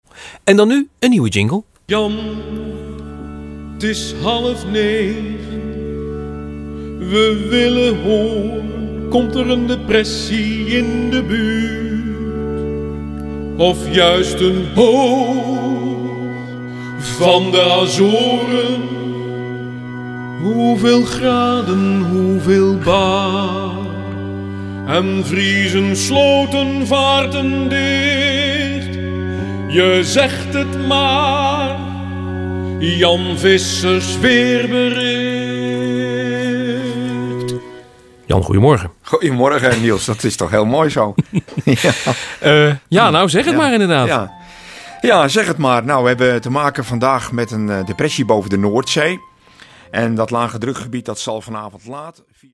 gezongen jingle